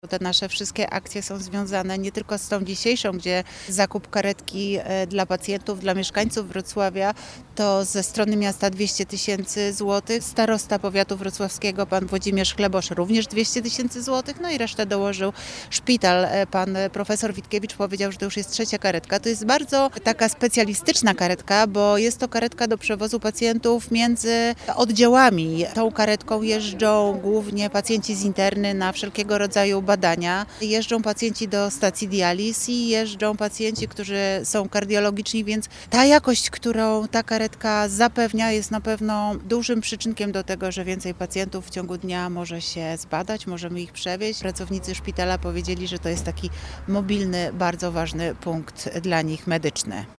Nowa karetka ma przede wszystkim skrócić czas oczekiwania pacjentów na transport oraz poprawić bezpieczeństwo i komfort przewozu osób wymagających specjalistycznej opieki. Mówi Renata Granowska, wiceprezydent Wrocławia.